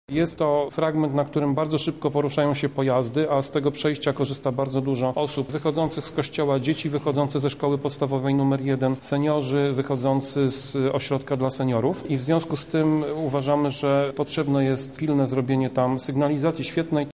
– Chcemy także wybudowania sygnalizacji świetlnej na ulicy Kunickiego w okolicy kościoła – dodaje Tomasz Pitucha: